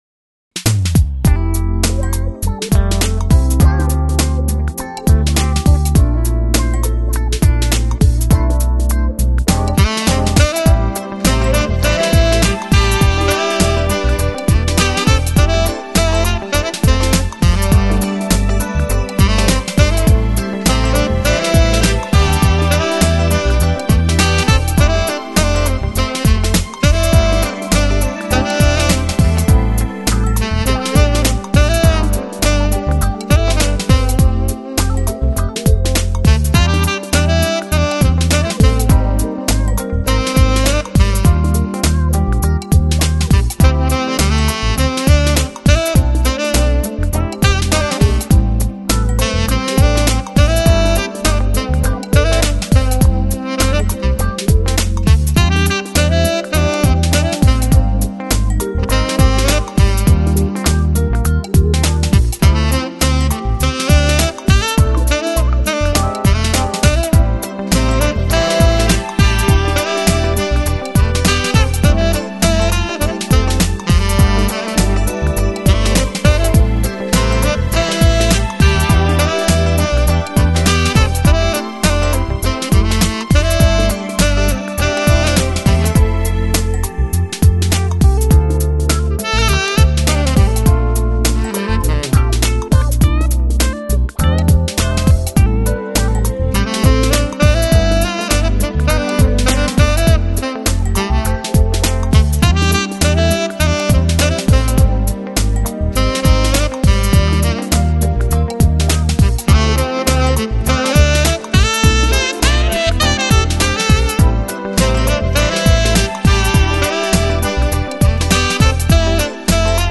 Lounge, Chill Out, Smooth Jazz, Easy Listening Год издания